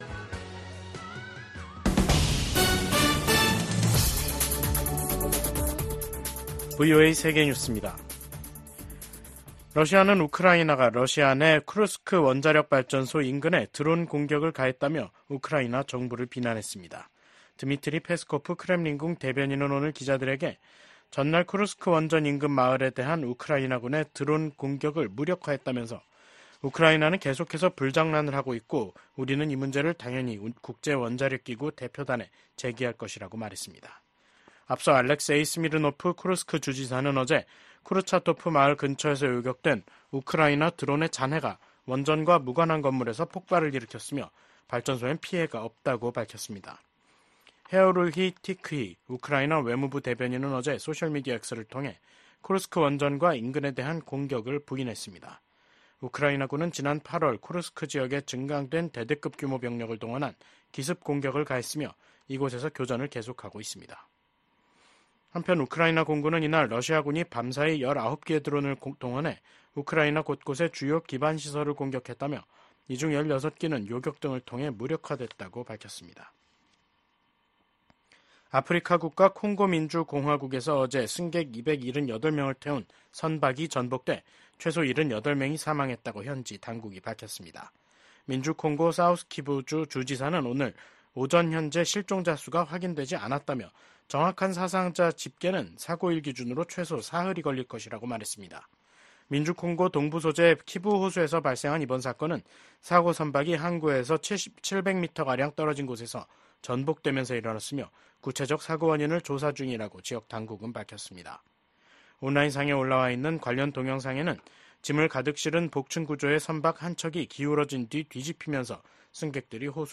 VOA 한국어 간판 뉴스 프로그램 '뉴스 투데이', 2024년 10월 4일 3부 방송입니다. 이시바 시게루 신임 일본 총리가 제안한 ‘아시아판 나토’ 구상에 대해 미국 하원의원들은 대체로 신중한 반응을 보였습니다. 김정은 북한 국무위원장은 윤석열 한국 대통령을 실명으로 비난하면서 미국과 한국이 북한 주권을 침해하려 할 경우 핵무기로 공격하겠다고 위협했습니다.